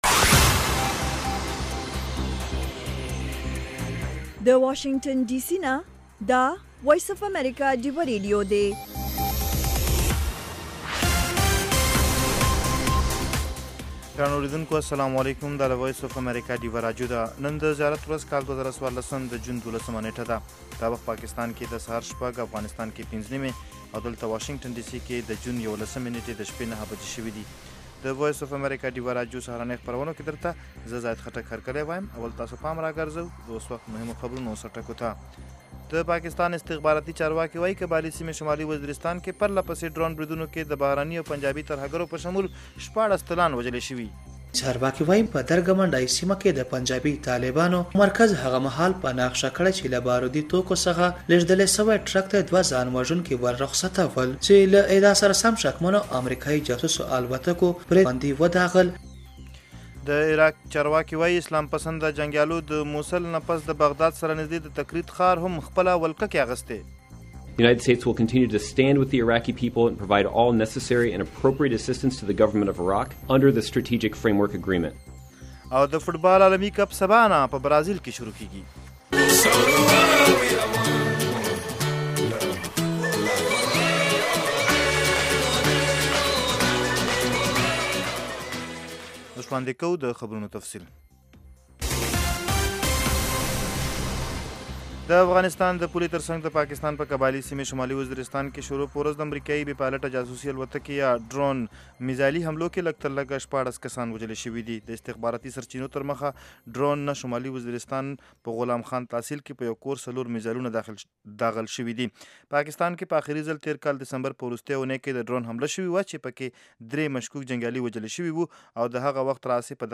خبرونه - 0100